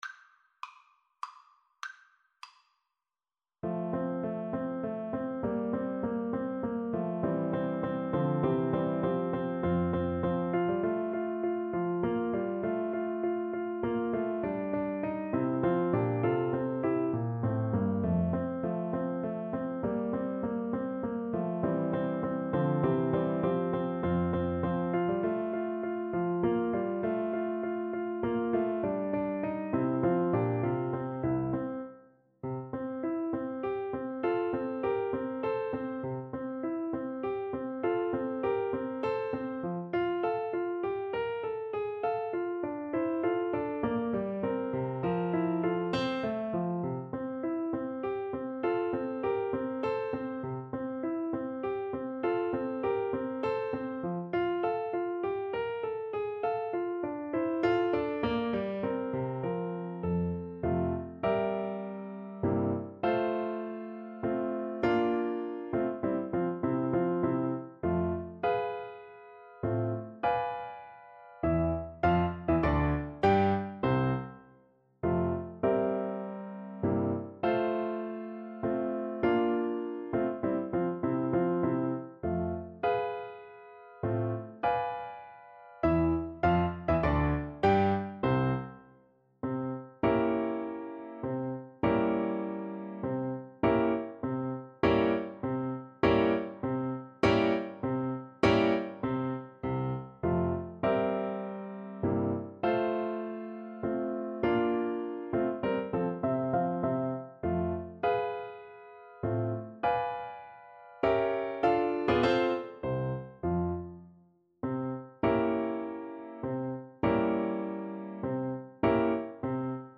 • Unlimited playalong tracks
Menuetto Moderato e grazioso
Classical (View more Classical Trombone Music)